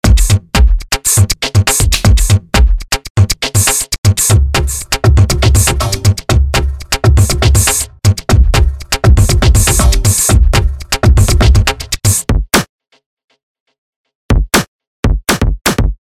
Die Grundlage bietet folgender Electro-Beat, eine Kombination aus Soniccouture Konkrete und Native Instruments Battery: